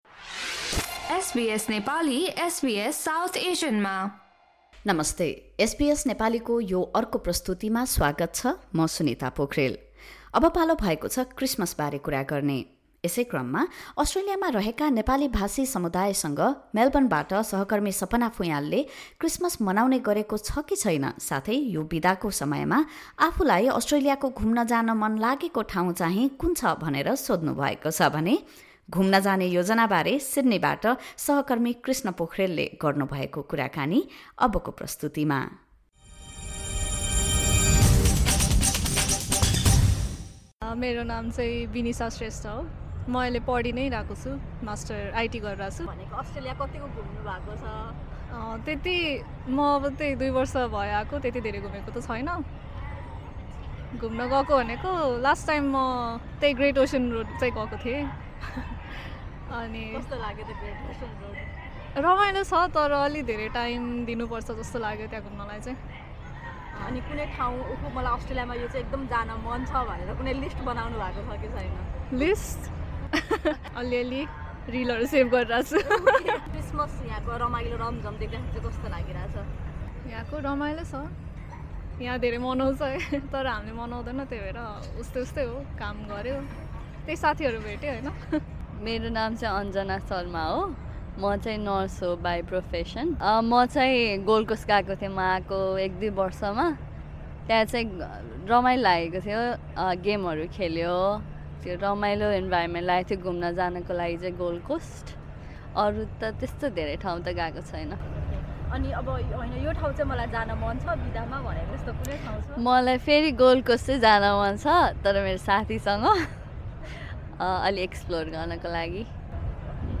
Some Nepali-speaking community members share their answers to these questions.